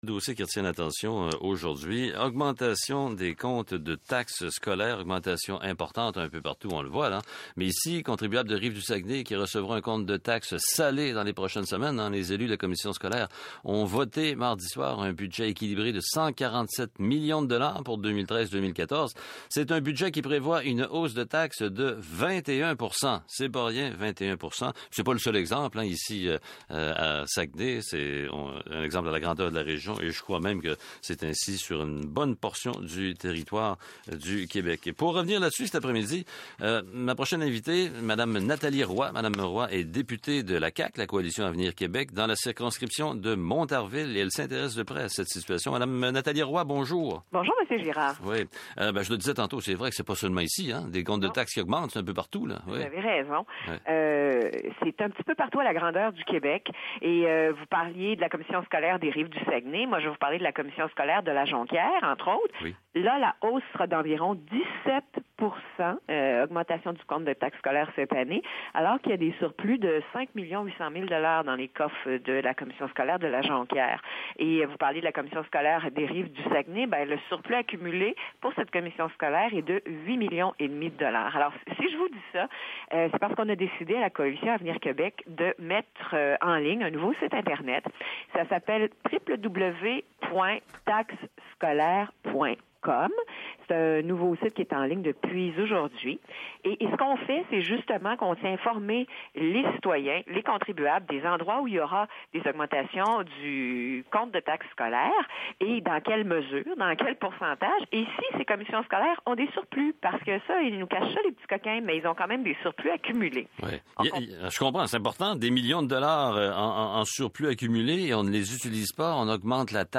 EntrevueRadioCanadaSaguenay26juin2013